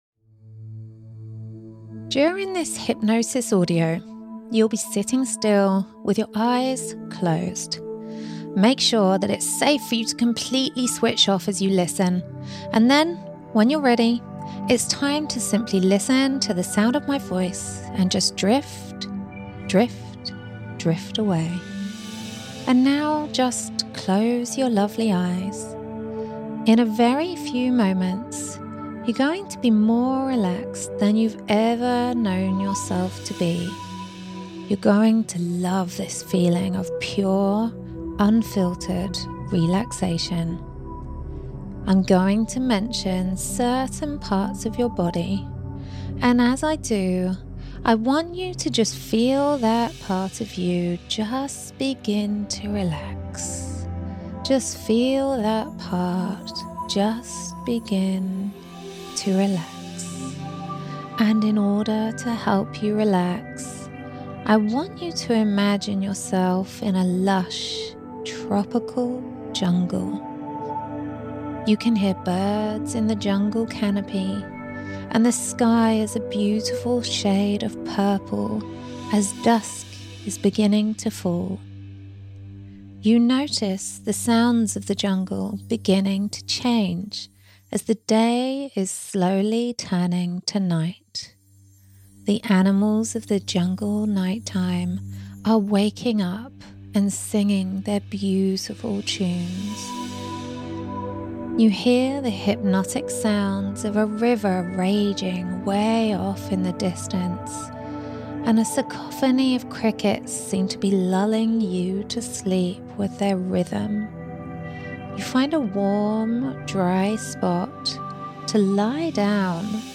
Hypnosis_for_Fear_of_Current_Events.mp3